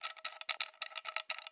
SFX_Unscrew_or just metal.wav